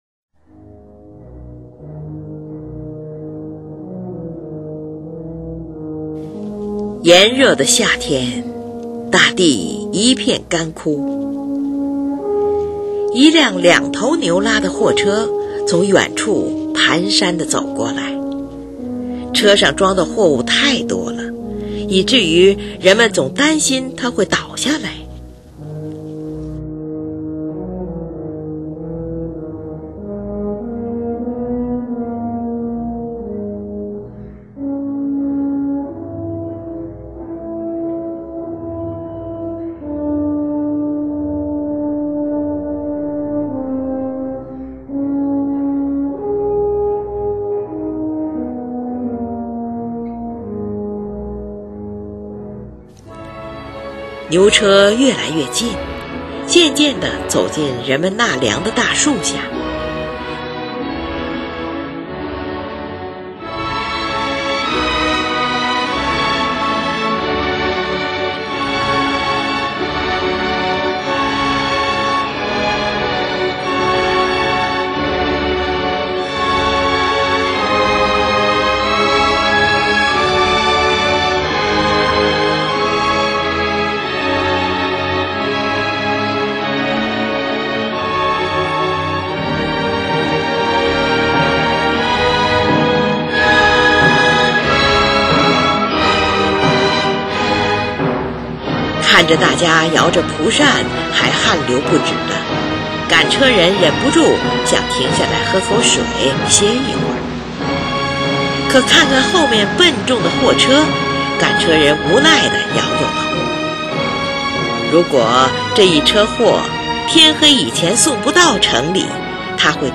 而在这背景上由大号奏出的一支驾车人之歌则表达了农民对他们的不自由和没有欢乐的艰苦劳动的悲痛感受。